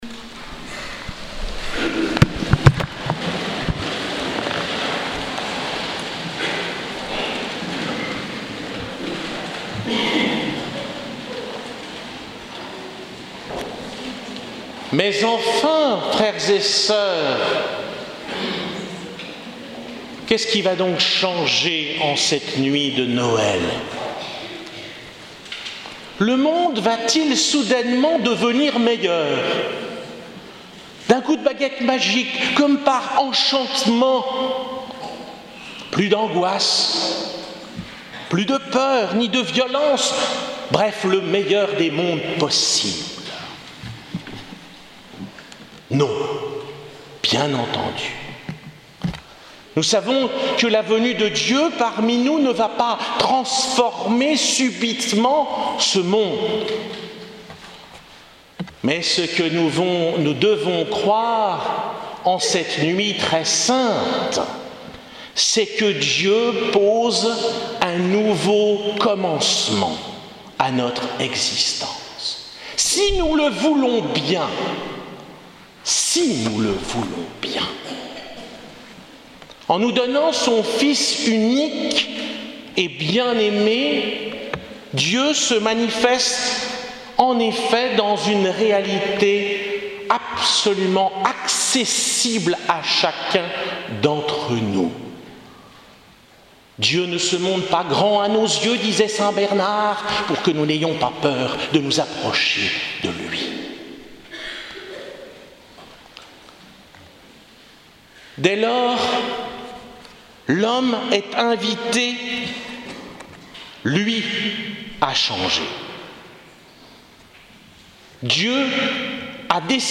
Homélie Messe de la Nuit de Noël 2017